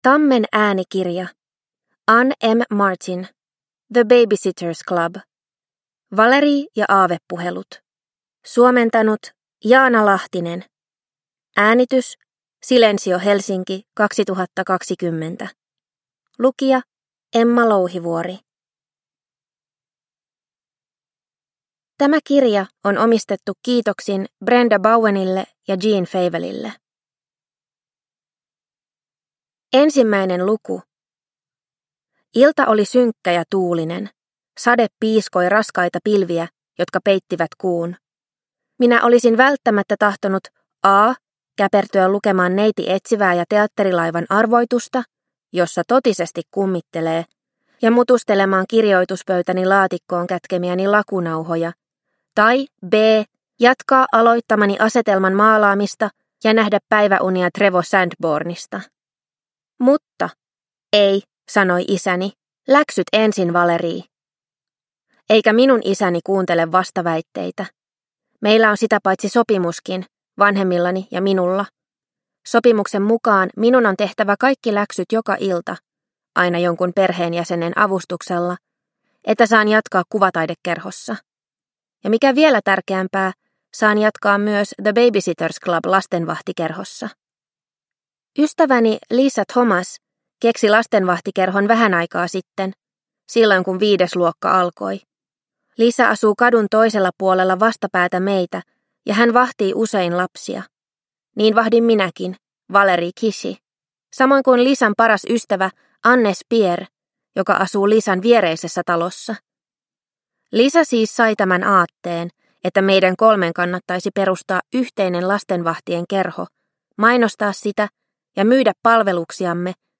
The Baby-Sitters Club. Valerie ja aavepuhelut – Ljudbok – Laddas ner